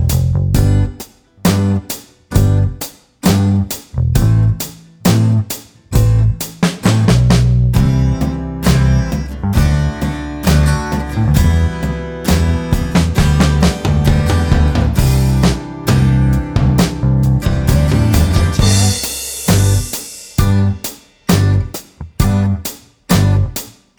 Minus Piano Pop (1970s) 4:08 Buy £1.50